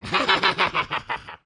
Sound of Antasma's laugh from Mario & Luigi: Dream Team
MLDT_-_Antasma's_Laugh.oga.mp3